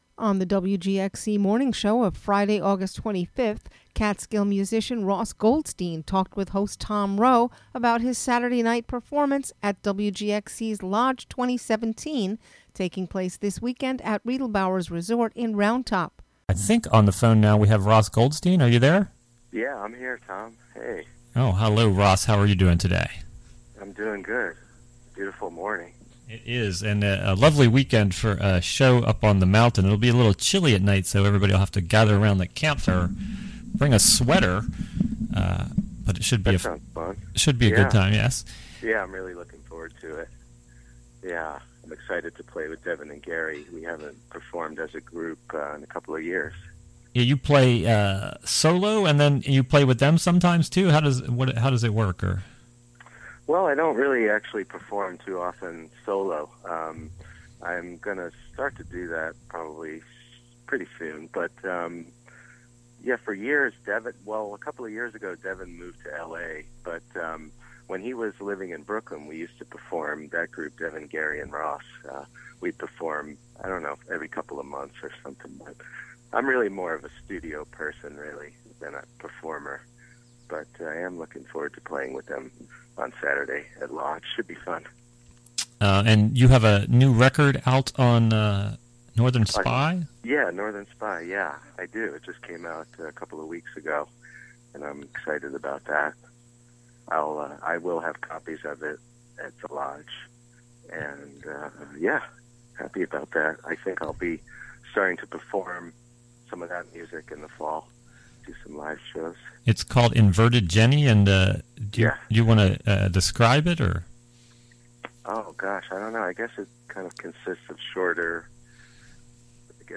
Interviewed
Recorded during the WGXC Morning Show of Fri., Aug. 25, 2017.